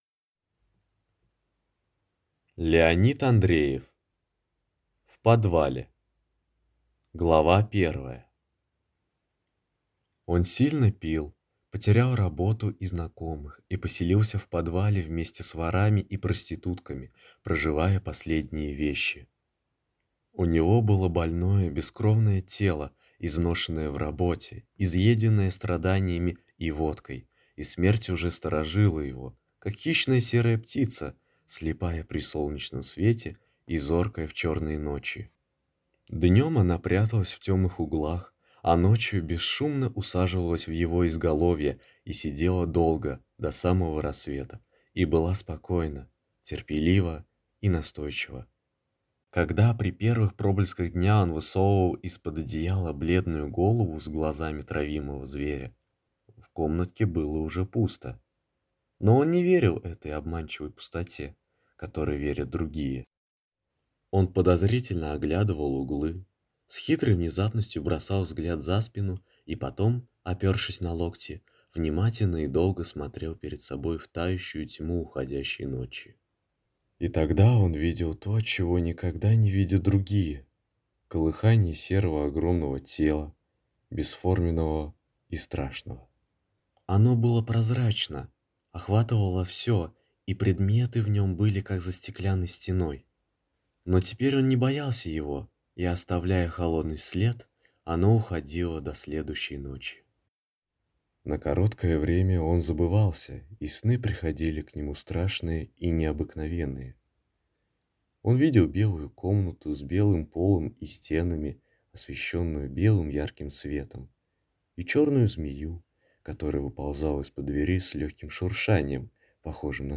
Аудиокнига В подвале | Библиотека аудиокниг